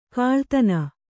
♪ kāḷtana